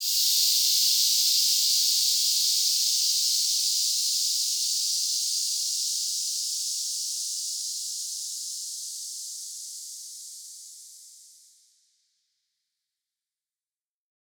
Index of /musicradar/shimmer-and-sparkle-samples/Filtered Noise Hits
SaS_NoiseFilterC-02.wav